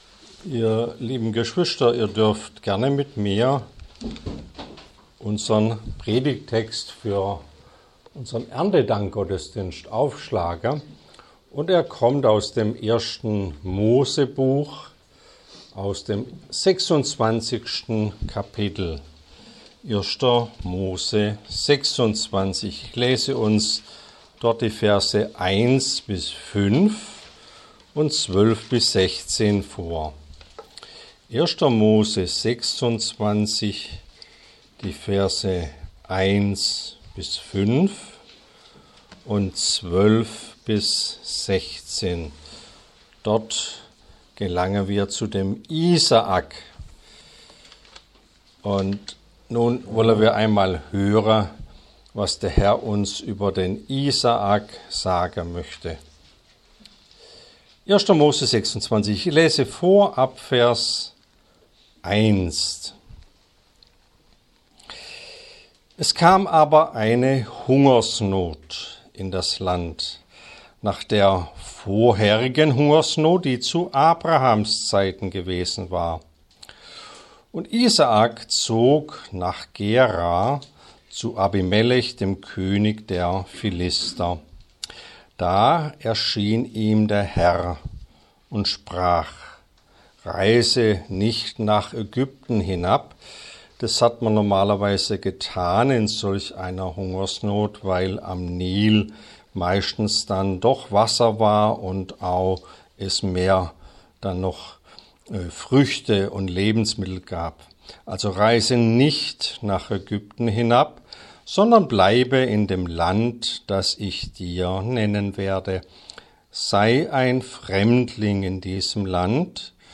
Alle Predigten Der Segen Isaaks – Erntedankgottesdienst 1 Oktober, 2023 Serie: Erntedankgottesdienst Bücher: 1.